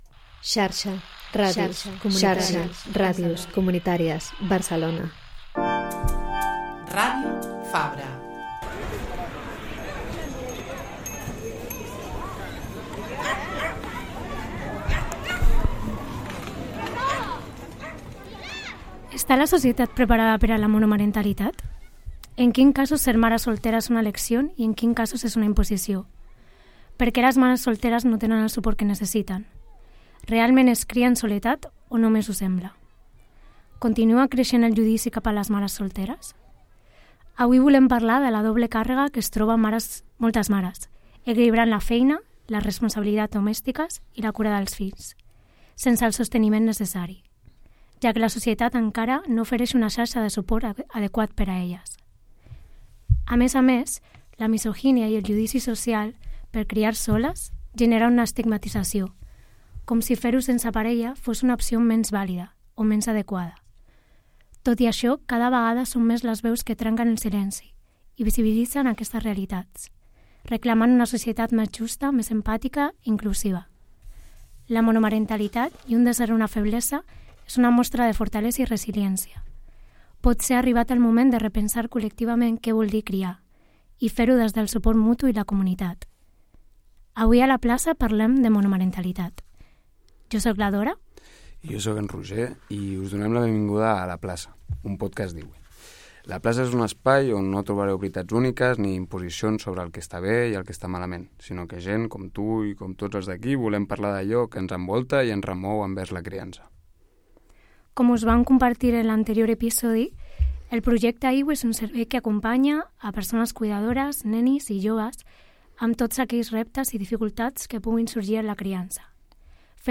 Conversem amb quatre mares que ens comparteixen la seva experiència en primera persona: com viuen el dia a dia, com gestionen la càrrega física i mental i com troben suport en la seva comunitat.